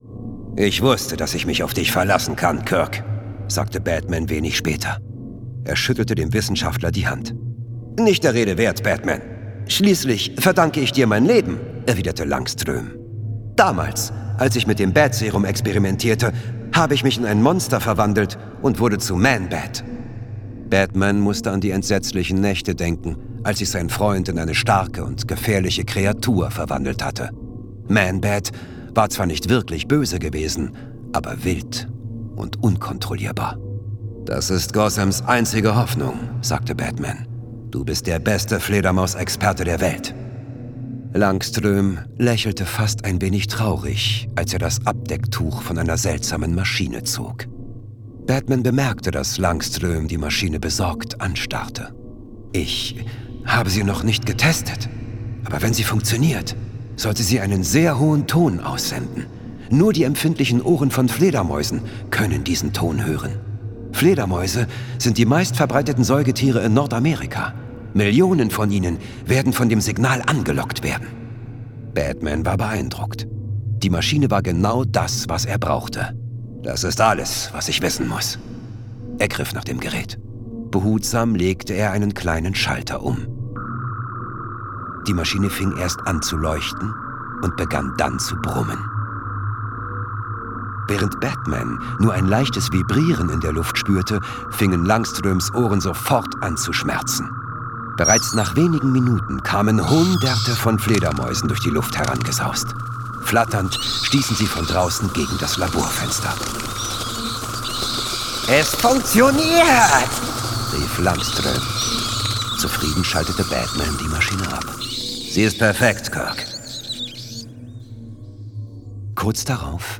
Torsten Michaelis (Sprecher)
Mit großer Geräusch- und Musikkulisse ist das ein herrlich spaßiges Comichörbuch für kleine und große Actionfans.
Torsten Michaelis, die deutsche Stimme von Sean Bean und Wesley Snipes, entführt seine Zuhörer mit seiner tiefen und facettenreichen Stimme in die knallbunte Comic-Kulisse.